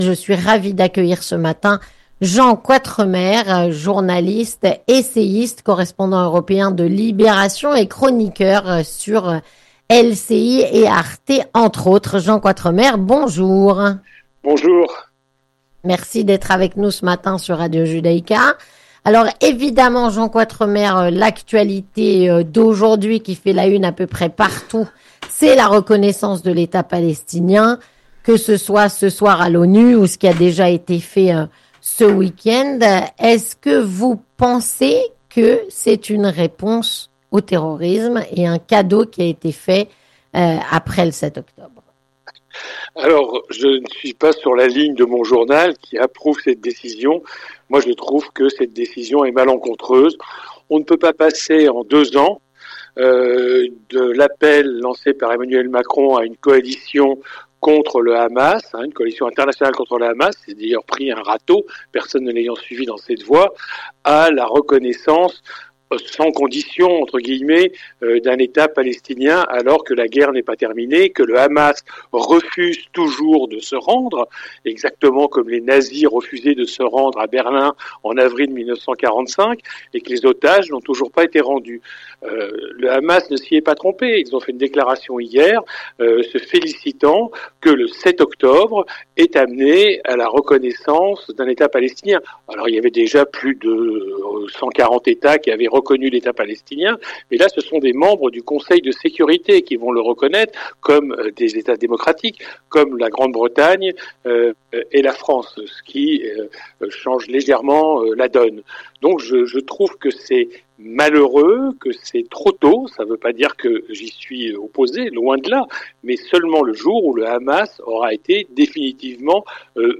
On en parle sans "langue de bois", ce matin, avec Jean Quatremer, journaliste, essayiste et correspondant à Bruxelles de "Libération".